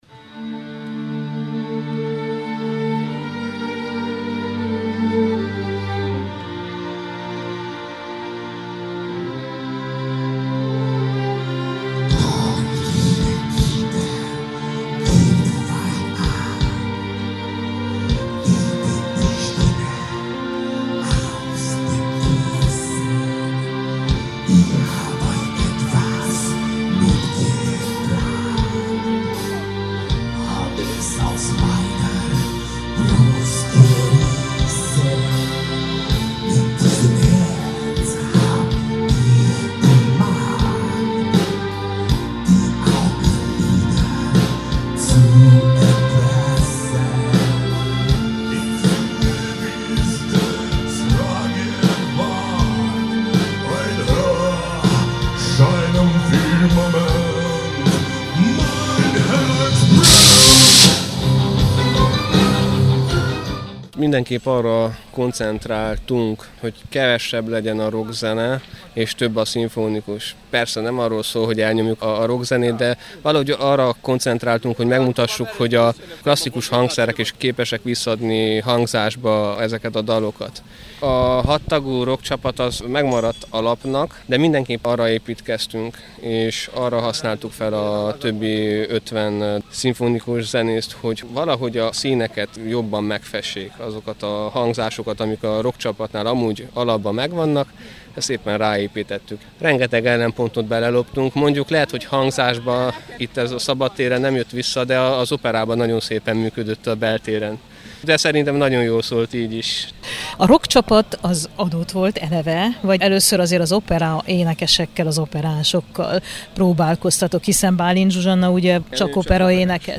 SYMPHONIC-ROCK-SHOW-.mp3